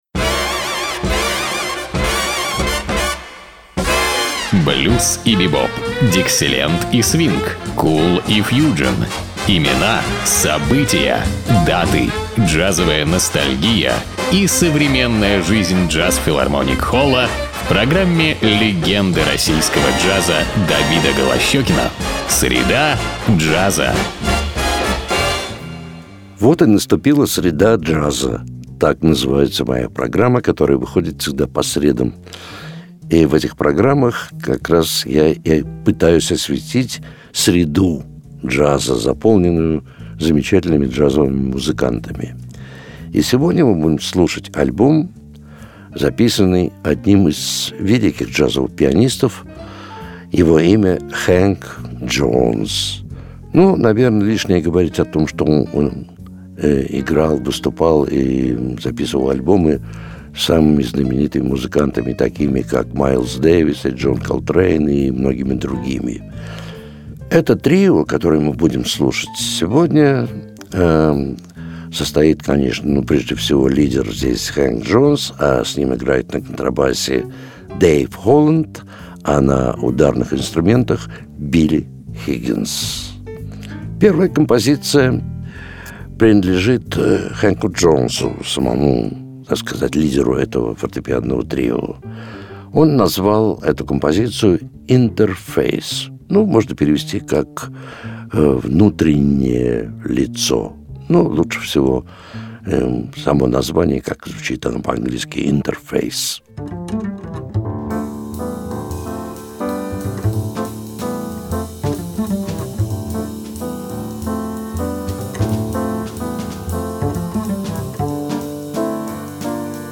фортепьяно
контрабас
ударные